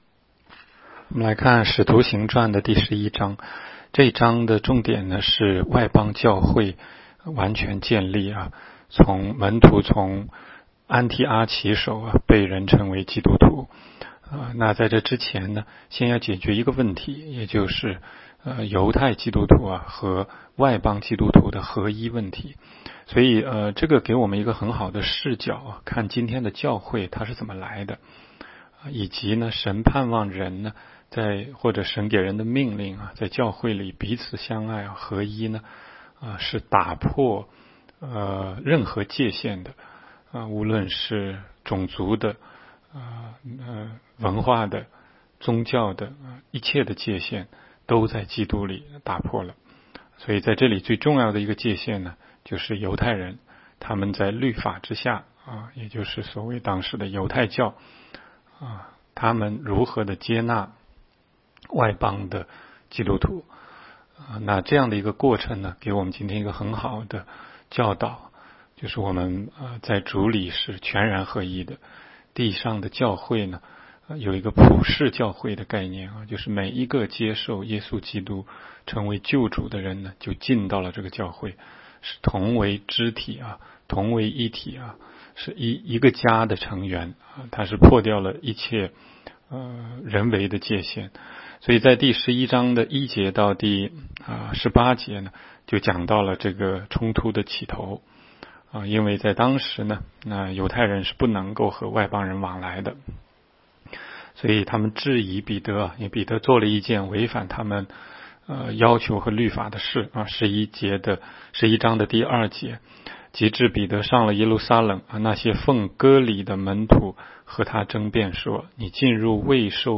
16街讲道录音 - 每日读经-《使徒行传》11章